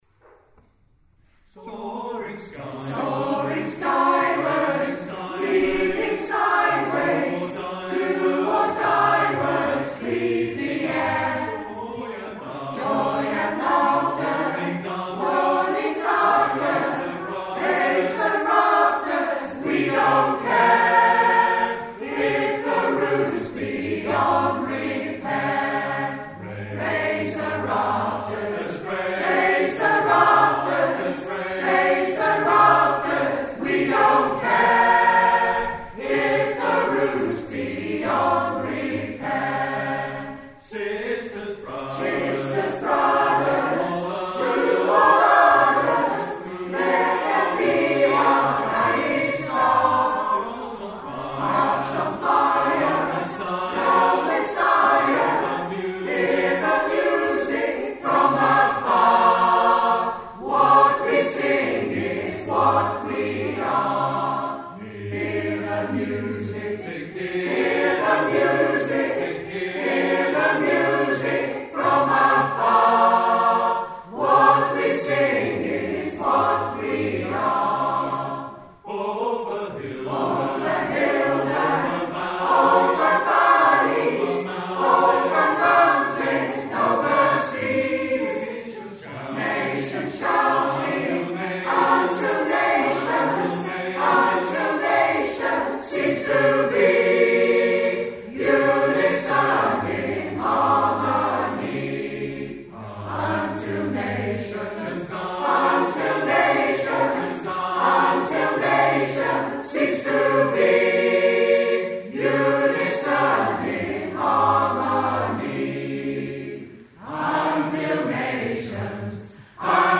Zvuková ukázka z vystoupení v kostele sv. Jan Evangelisty v Towbridge